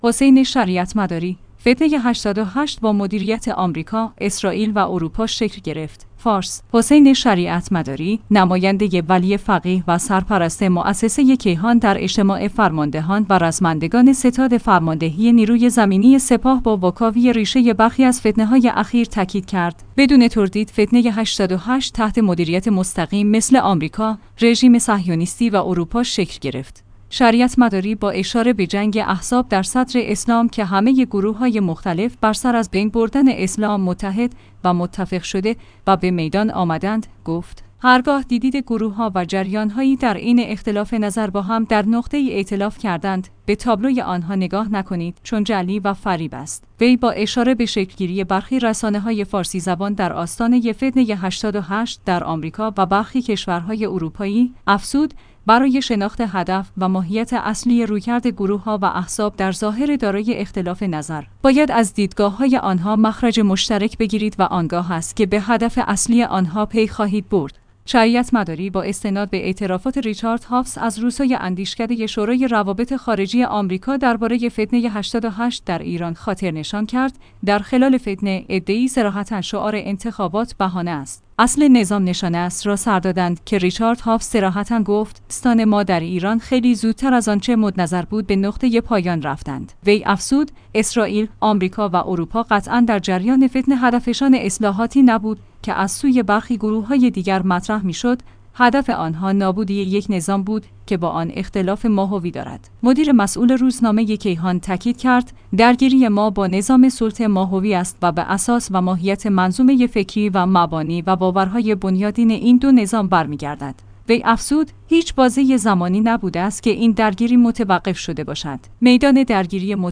فارس/ حسین شریعتمداری، نماینده ولی فقیه و سرپرست موسسه کیهان در اجتماع فرماندهان و رزمندگان ستاد فرماندهی نیروی زمینی سپاه با واکاوی ریشه برخی از فتنه‌های اخیر تاکید کرد: بدون تردید فتنه ۸۸ تحت مدیریت مستقیم مثل آمریکا، رژیم صهیونیستی و اروپا شکل گرفت.